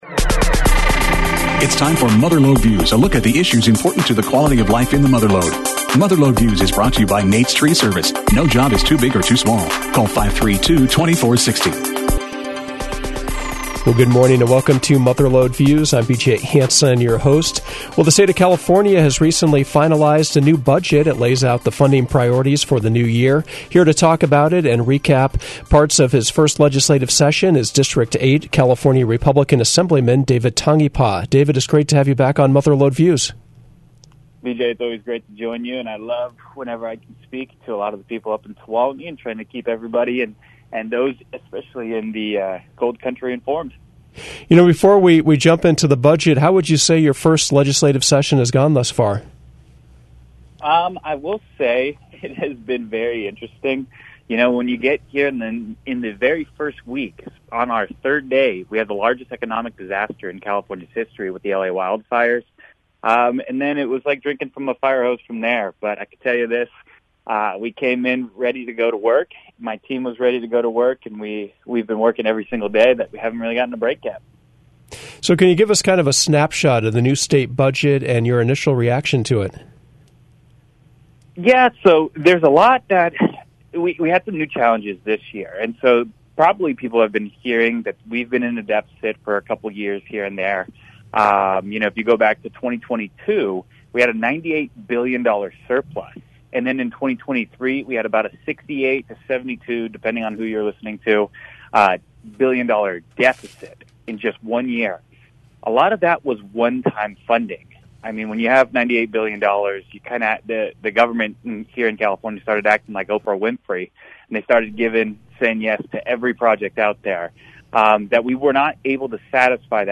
Mother Lode Views featured a conversation with California Republican Assemblyman David Tangipa, who represents the Mother Lode region. Tangipa detailed the new California budget that took effect on July 1st, and explained why he voted in opposition.